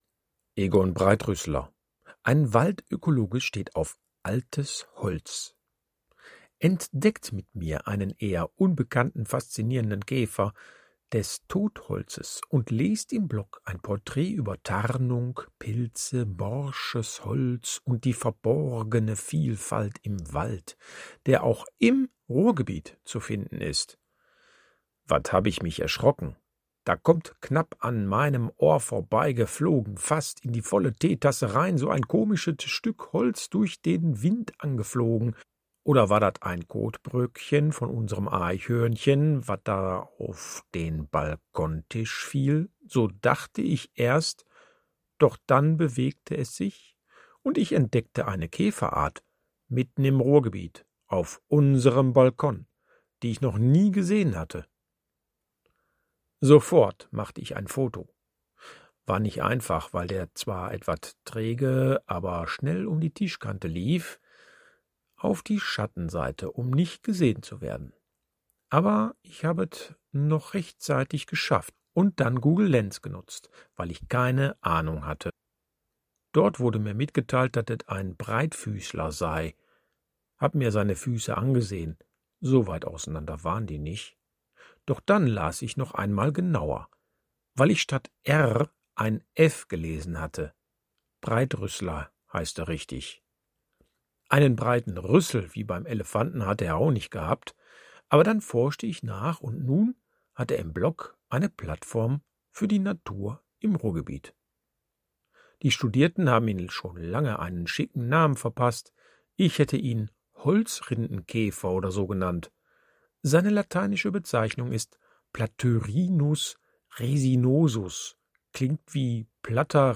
Egon Breitrüssler – Ein Waldökologe steht auf altes Holz I +Hördatei auf Ruhrdeutsch